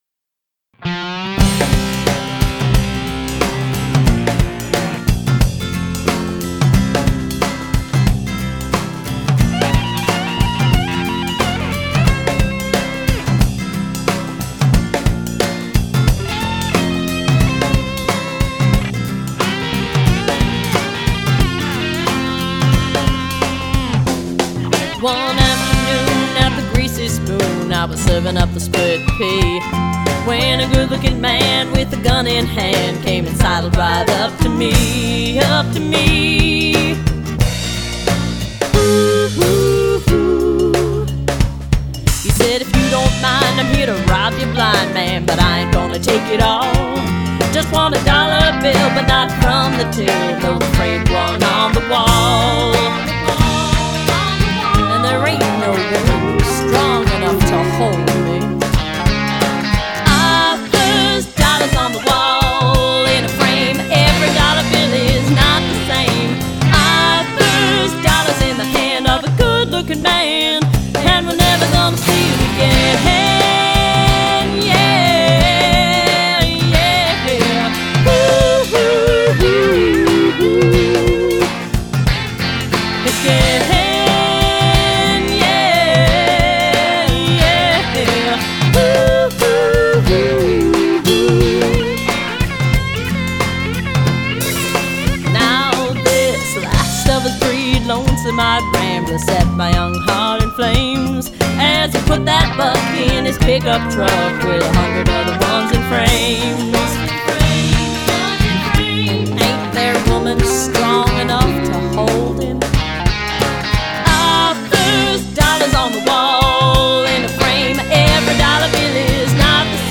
vocals
additional guitars